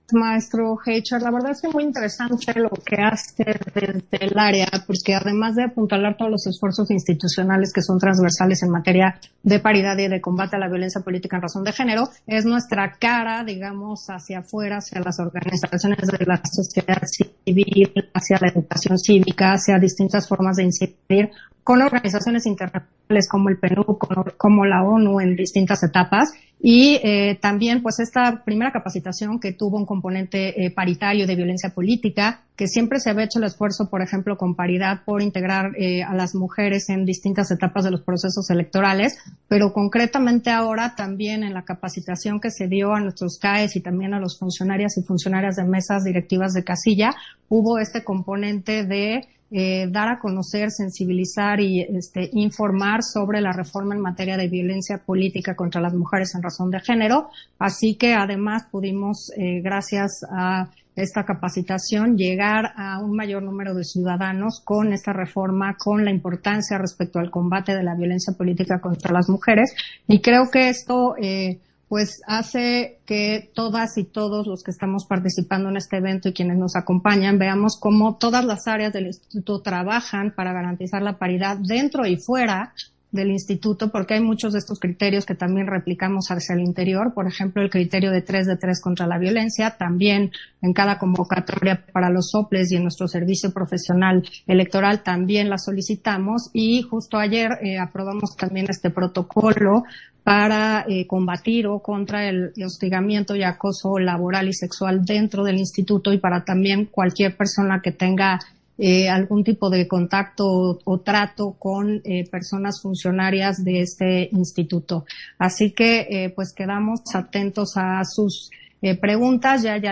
010721_AUDIO_INTERVENCIÓN CONSEJERA CARLA HUMPHREY-OCTAVO CONVERSATORIO - Central Electoral